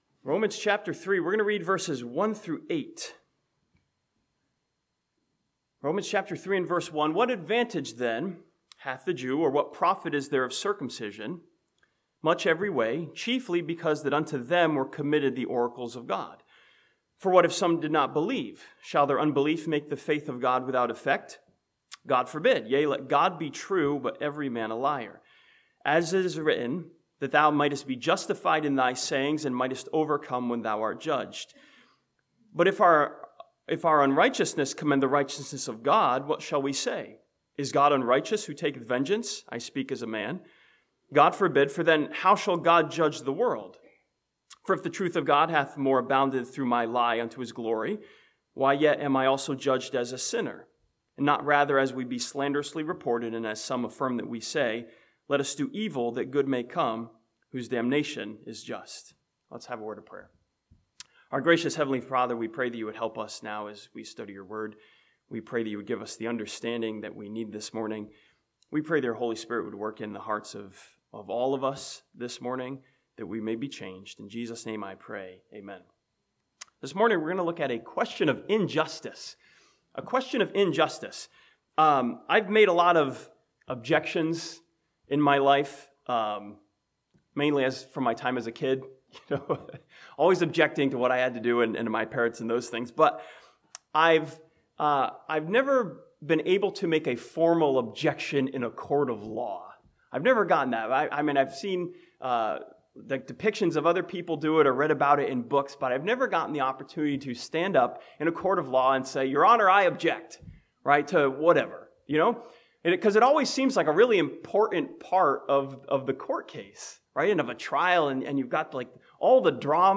This sermon from Romans chapter 3 ask a question of injustice: "Is our Judge unjust?" and finds the answer from Scripture.